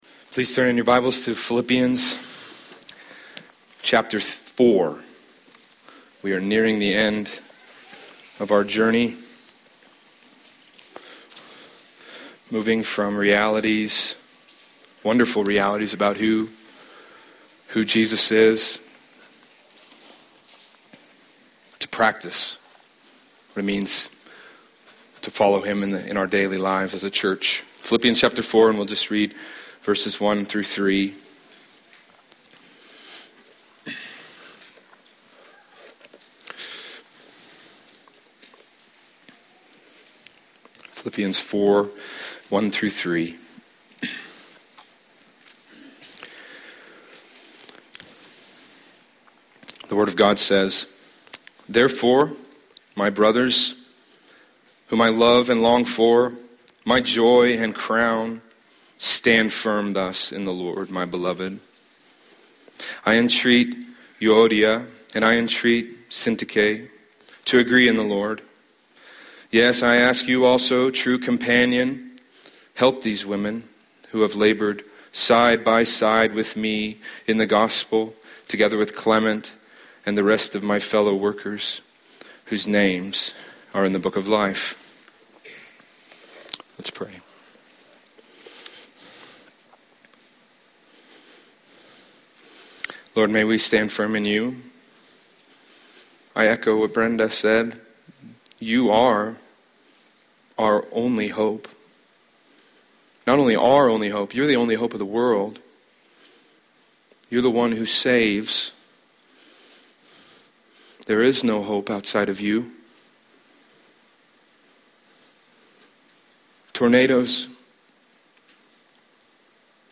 Sermons | Ridgeview Bible Church